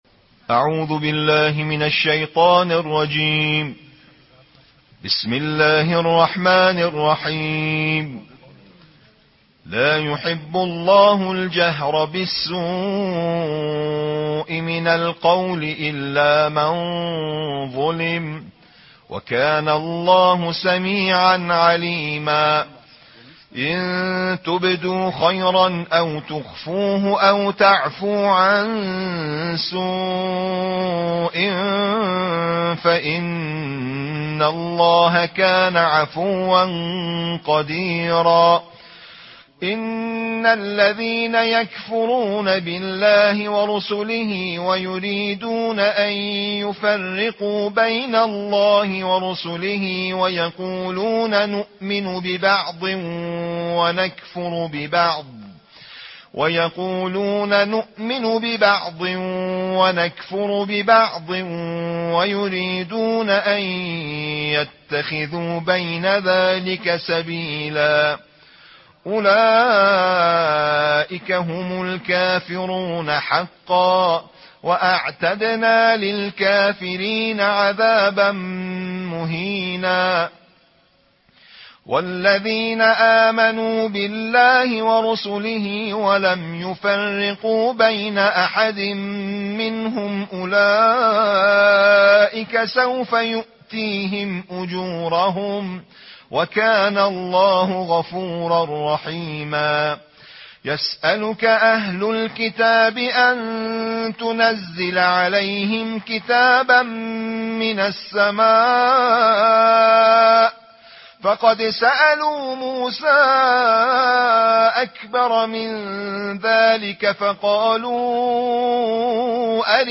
Récitation quotidienne du Coran : Tarteel du Juz 6